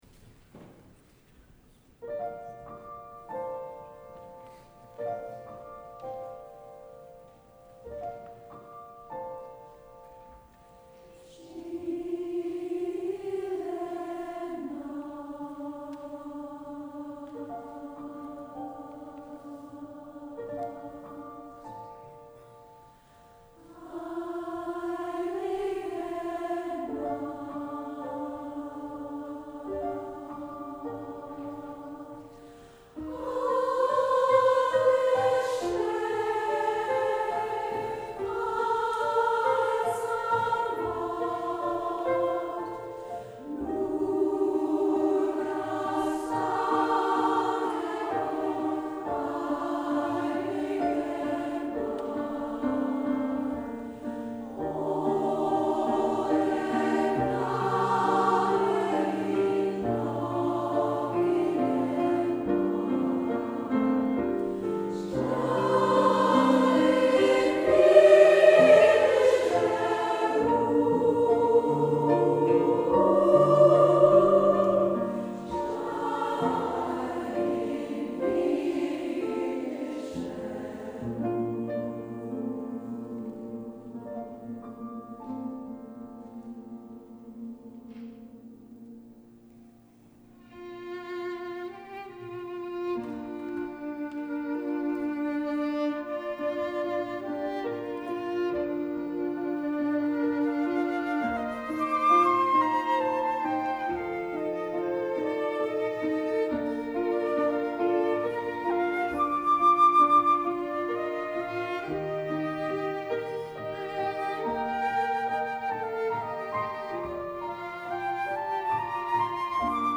2-part equal voices, piano, violin, flute